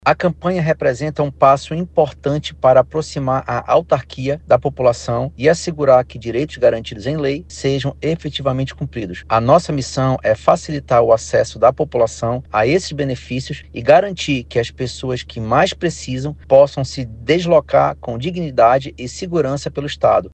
O projeto ocorre em parceria com a Secretaria de Estado dos Direitos da Pessoa com Deficiência (SePcD) e busca facilitar o processo para quem ainda não possui os documentos, explica o secretário adjunto SePcD, Adriano Reis.